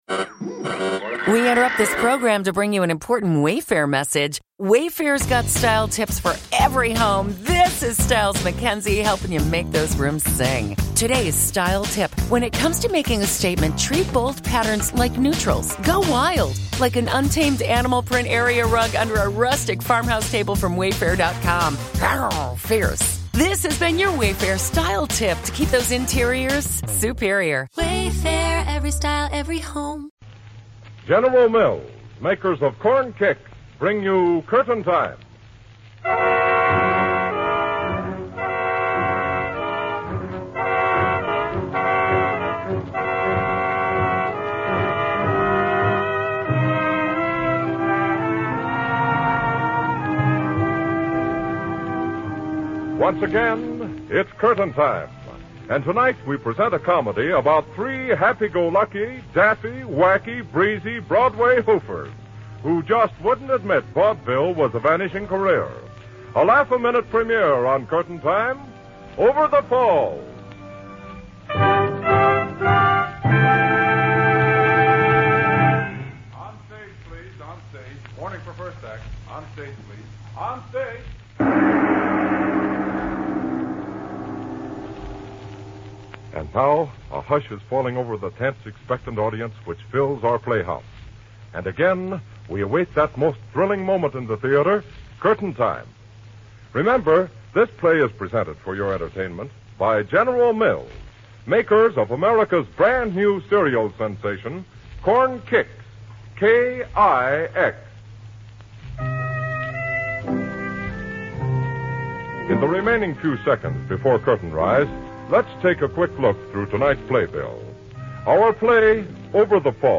Curtain Time was a popular American radio anthology program that aired during the Golden Age of Radio. It was known for its romantic dramas and its unique presentation style that aimed to recreate the atmosphere of attending a live theater performance.Broadcast History: 1938-1939: The show first aired on the Mutual Broadcasting System from Chicago. 1945-1950: It had a much more successful run on ABC and NBC, gaining a wider audience and greater popularity. Format and Features: "Theater Atmosphere": The show used sound effects and announcements to evoke the feeling of being in a theater, with an announcer acting as an usher and reminding listeners to have their tickets ready.